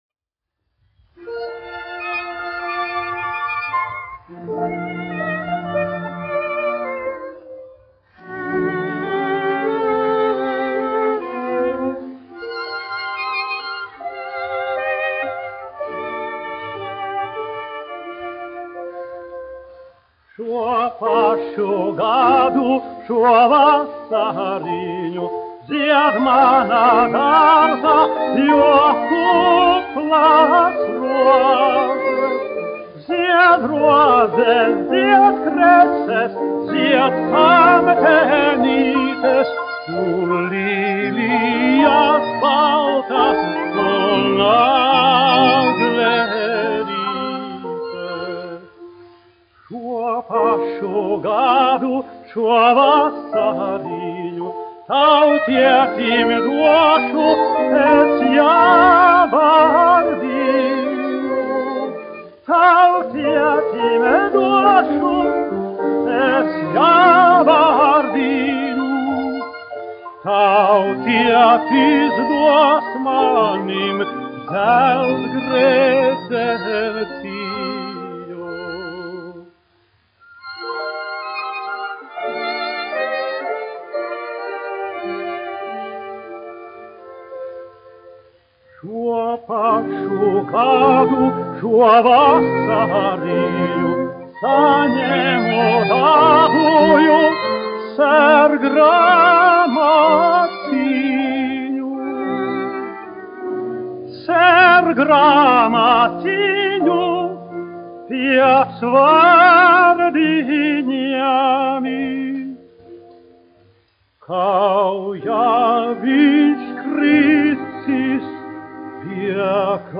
1 skpl. : analogs, 78 apgr/min, mono ; 25 cm
Dziesmas (augsta balss) ar klavieru trio
Skaņuplate